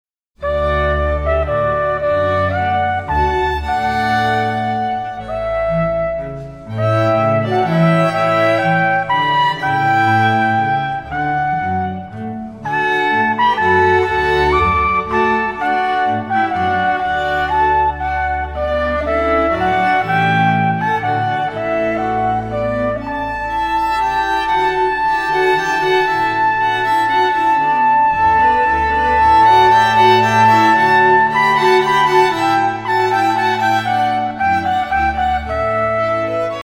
Trompete
Violine
Viola
Violoncello
Contrabass
in der Auferstehungskirche Neu-Rum